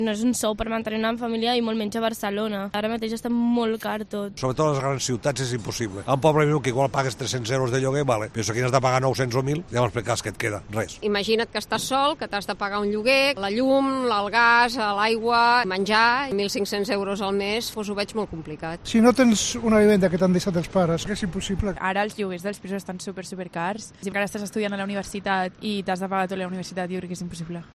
Ciudadanos y ciudadanas de Barcelona
En COPE hemos salido a la calle para conocer las opiniones de los ciudadanos y ciudadanas de Barcelona.